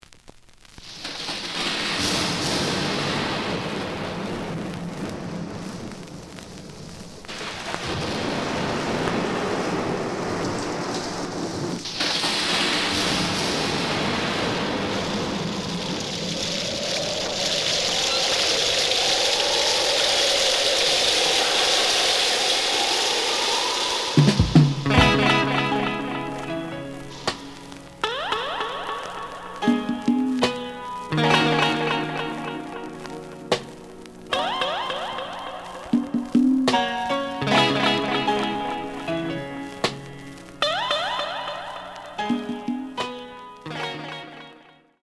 The audio sample is recorded from the actual item.
●Genre: Soul, 70's Soul
Some click noise on middle of both sides due to a bubble.